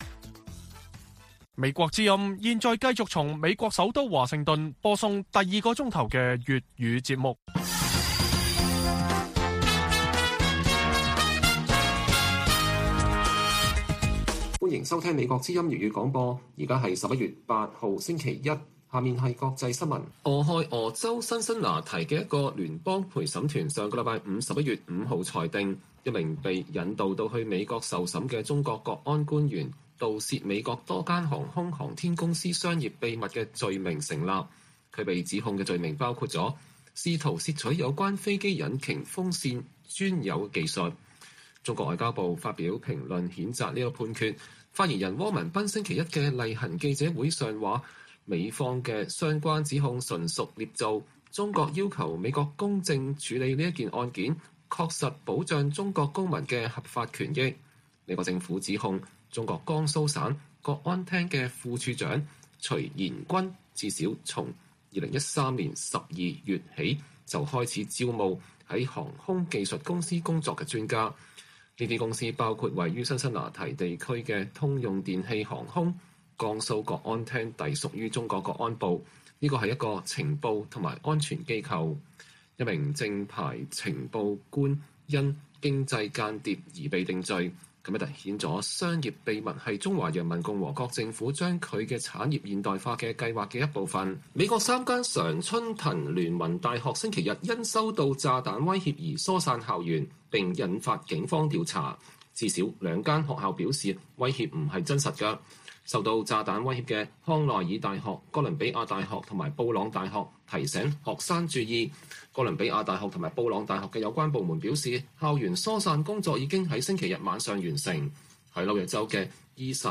粵語新聞 晚上10-11點: 被引渡至美受審的江蘇國安官員的經濟間諜罪名成立